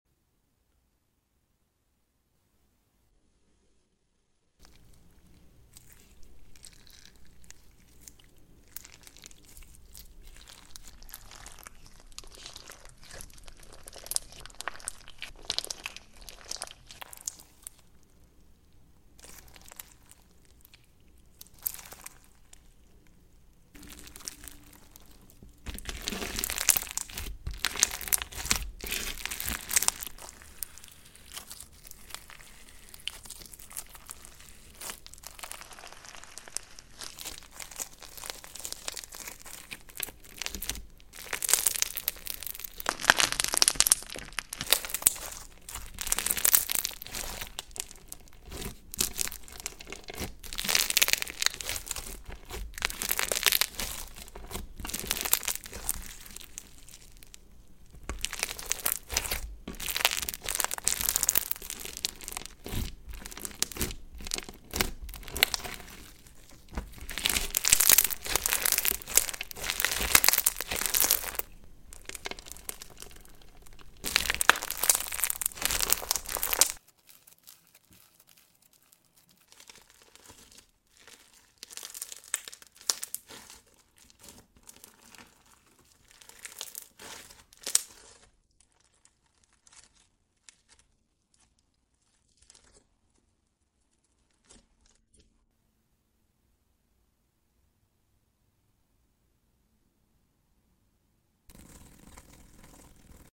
Satisfying slime tingles & ASMR sound effects free download
Satisfying slime tingles & ASMR in 3D | Best with headphones/earbuds
Unscented silica crunch bomb